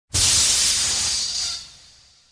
Gas.wav